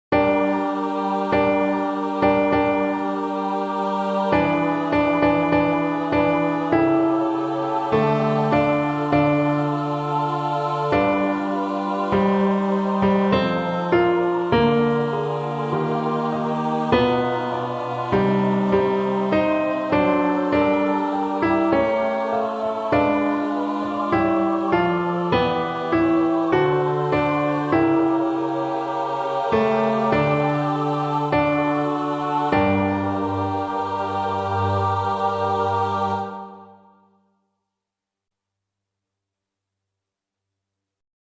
Ténors